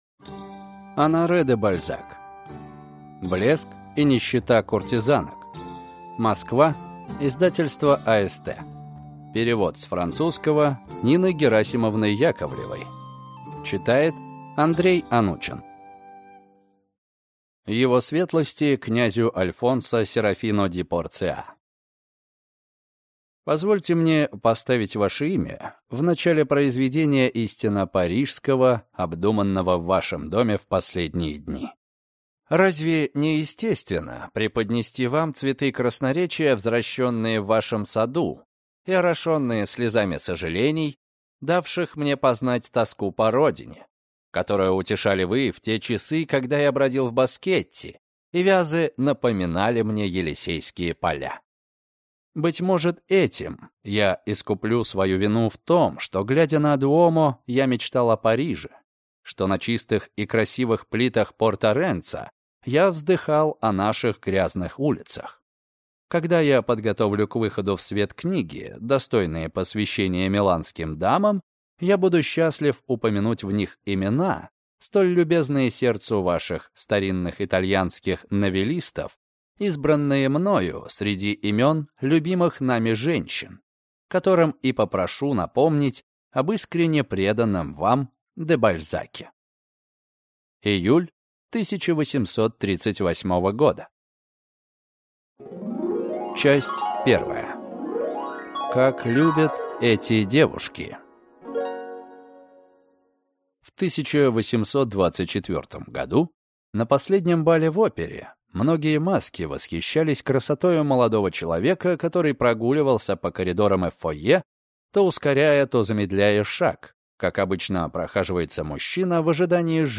Аудиокнига Блеск и нищета куртизанок | Библиотека аудиокниг